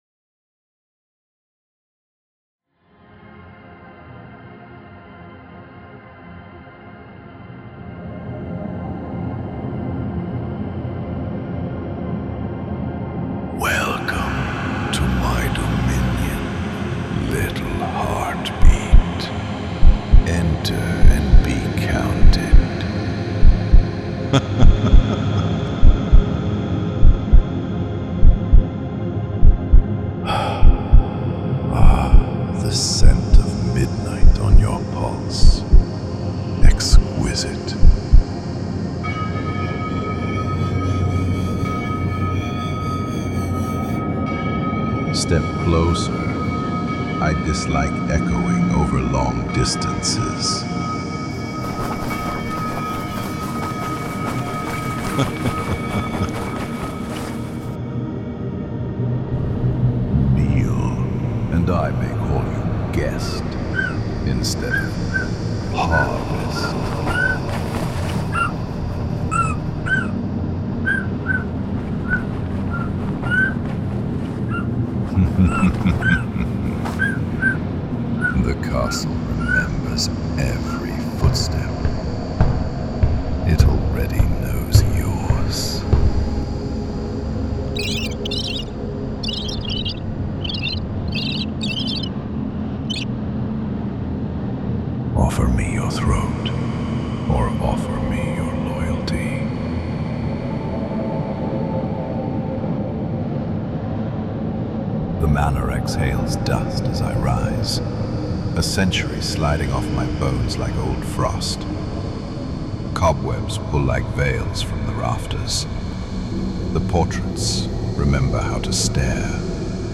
Type: Samples
‘Halloween Demon & Lord of the Vampire’ by Immense Sounds is a top-quality pack for your Halloween needs featuring Demon & Vampire Phrases/Words/Extras & Samples.
This top-quality pack features Demon & Lord of the Vampire Voices and phrases, one words, extras and samples which includes Bad Weather, Gusts, Hallucination Bells, Strange Pads, Bats, Tension, Demonic, Spooky Dimensions and much more.
8 x Demon Extras (7 x Laughs 1 x Sigh)
11 x Vampire Extras (7 x Laughs 2 x Sighs 2 x Exhales)